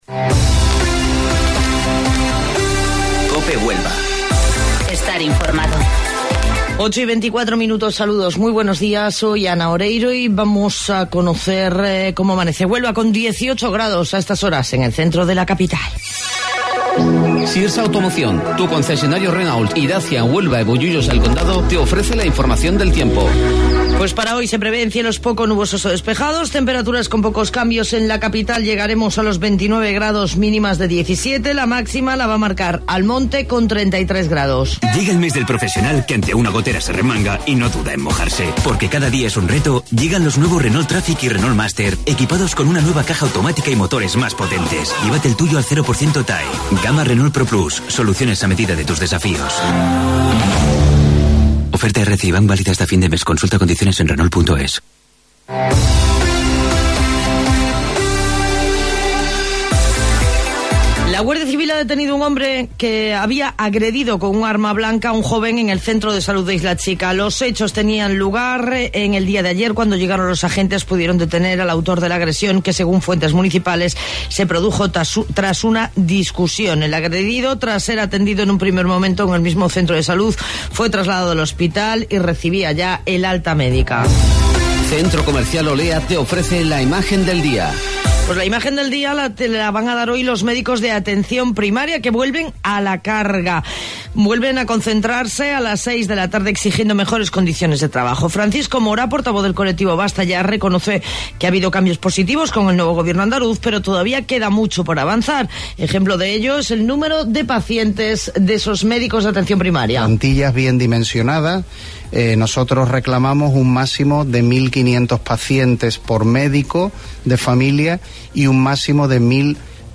AUDIO: Informativo Local 08:25 del 10 de Octubre